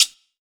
PSGUIRO.wav